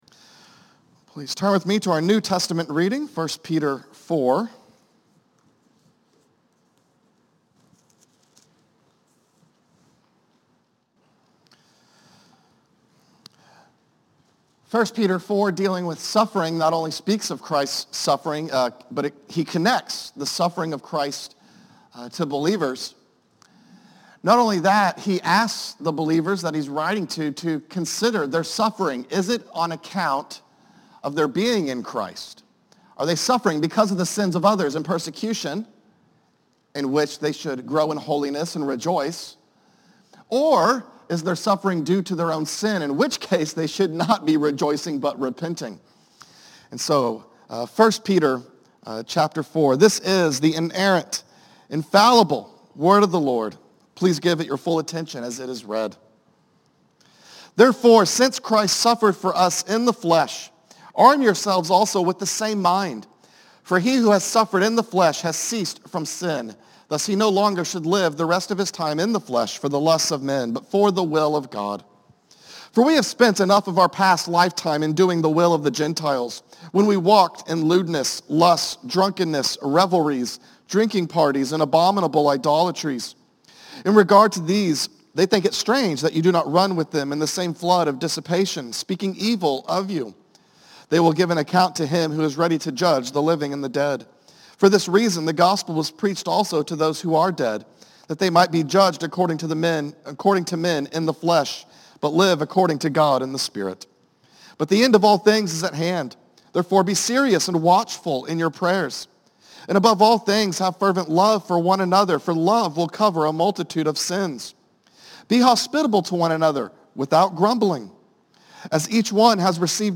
A message from the series "Habakkuk."